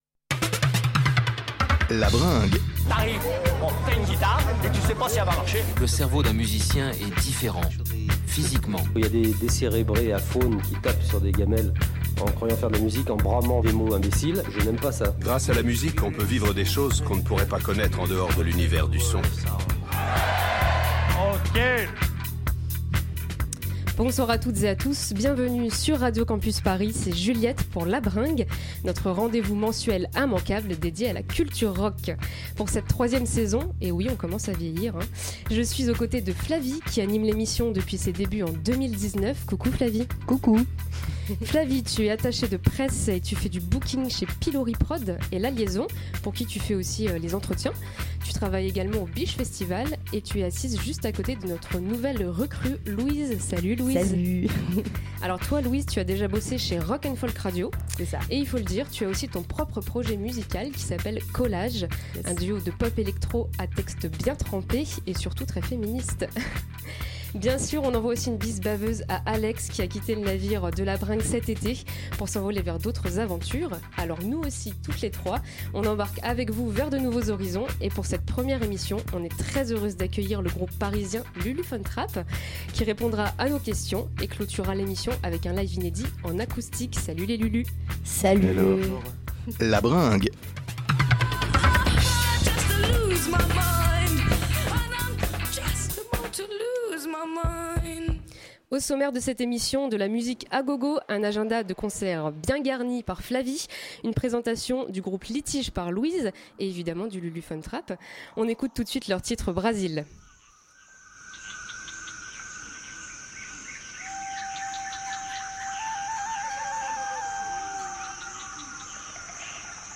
Lors de cette émission l'équipe de La Bringue a reçu Lulu Van Trapp pour une interview et une live session en fin d'émission.